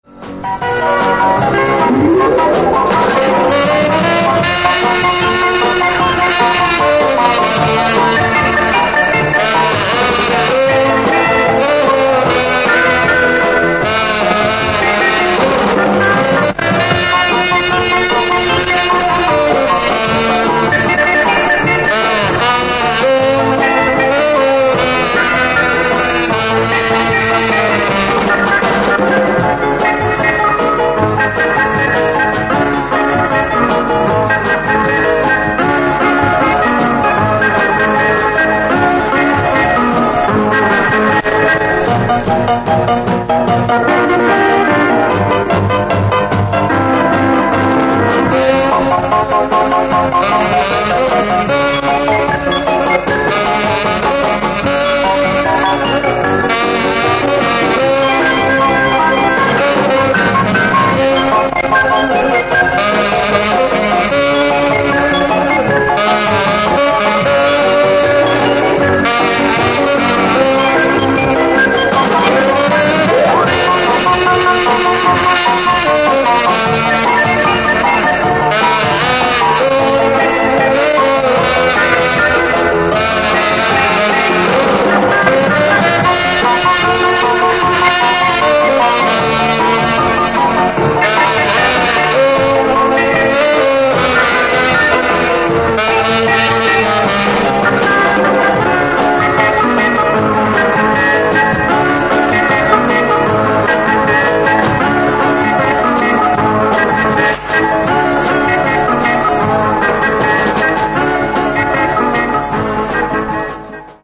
Три оркестровых инструменталки 60-х для опознания (повтор)
Первая записана немного не с начала.
instrumental-v-ispolnenii-estradnogo-orkestra-(zapis-60-h-godov)-1 (1).mp3